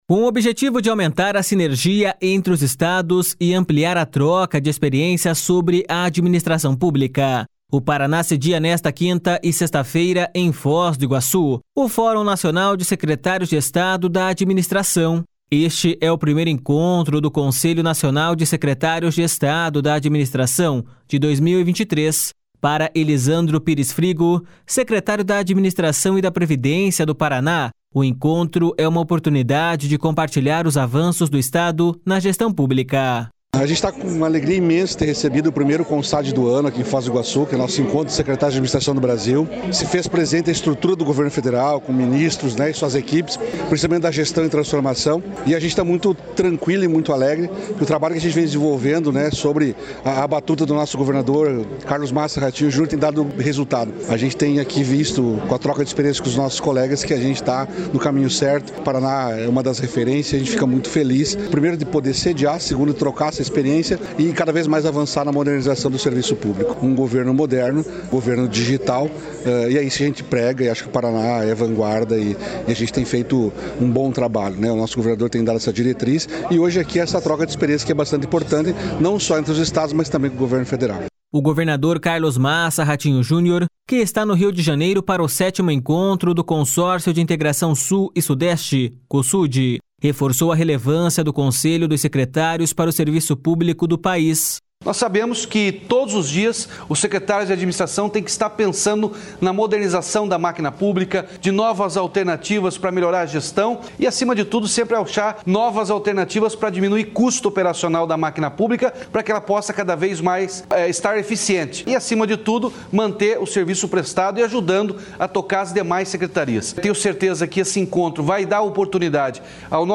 Este é o primeiro encontro do Conselho Nacional de Secretários de Estado da Administração de 2023. Para Elisandro Pires Frigo, secretário da Administração e da Previdência do Paraná, o encontro é uma oportunidade de compartilhar os avanços do Estado na gestão pública.// SONORA ELISANDRO PIRES FRIGO.//
O governador Carlos Massa Ratinho Junior, que está no Rio de Janeiro para o 7º Encontro do Consórcio de Integração Sul e Sudeste, Cosud, reforçou a relevância do conselho dos secretários para o serviço público do país.// SONORA RATINHO JUNIOR.//
Segundo o presidente do Conselho e secretário da Administração do Amazonas, Fabrício Barbosa, o momento é de interlocução.// SONORA FABRÍCIO BARBOSA.//